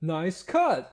voiced sfx
nicecut1.ogg